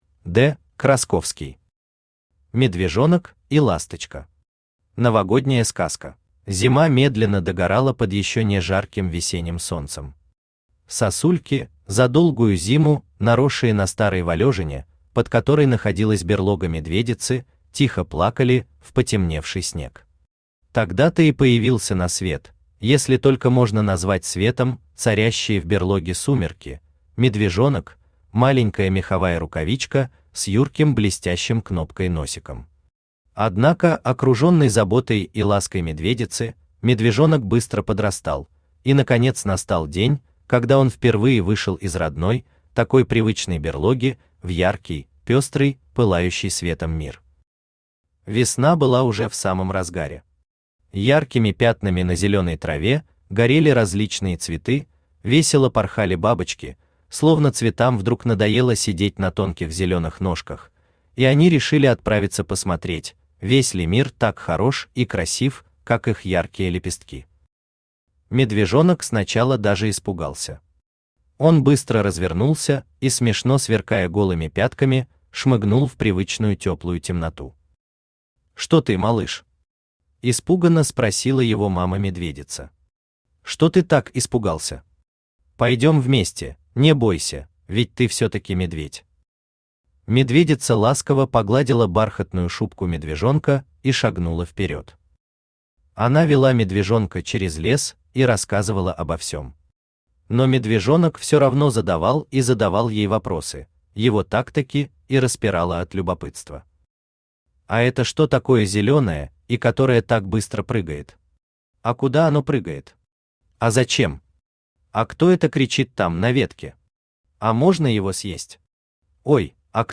Аудиокнига Медвежонок и Ласточка. Новогодняя сказка | Библиотека аудиокниг
Новогодняя сказка Автор Д. Красковский Читает аудиокнигу Авточтец ЛитРес.